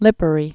(lĭpə-rē, lēpä-) Formerly Ae·o·li·an Islands (ē-ōlē-ən)